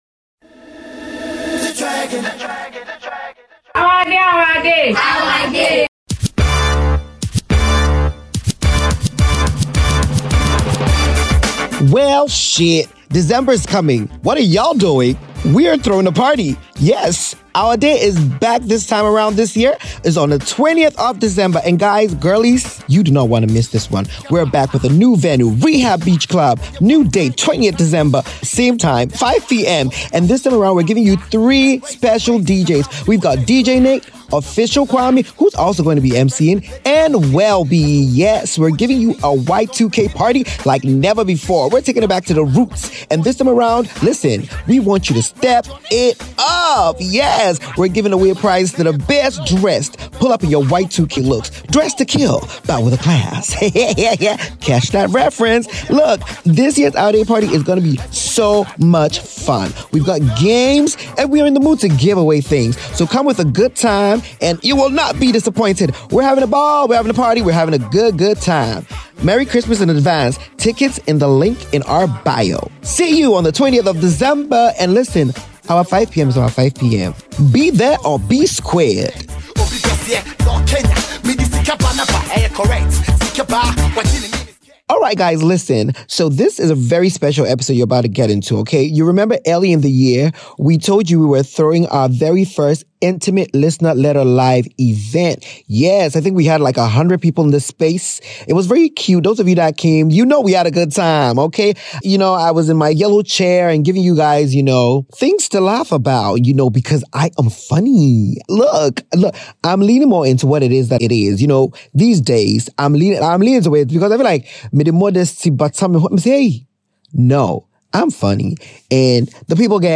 Missed out on our first intimate Listener Letter experience where we read out your letters to an audience and talked about them? You're lucky we recorded it, because opinions got pretty wild!